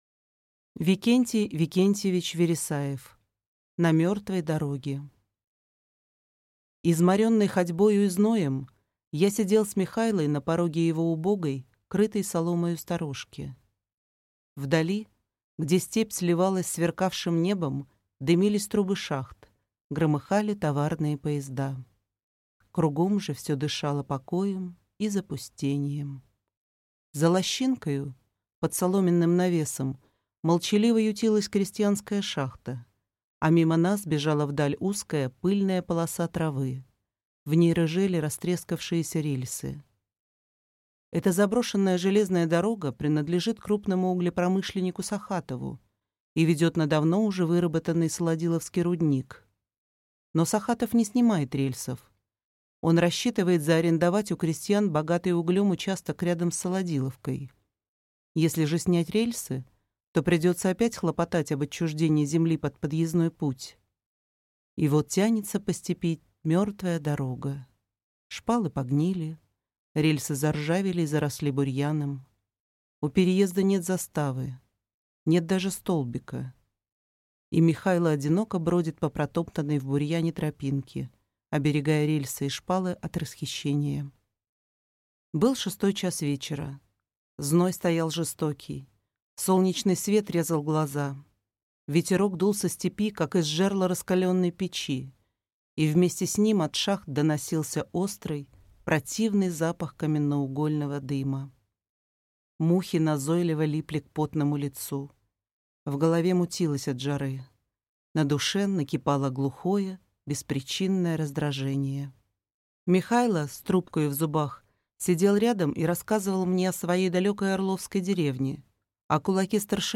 Аудиокнига На мёртвой дороге | Библиотека аудиокниг
Прослушать и бесплатно скачать фрагмент аудиокниги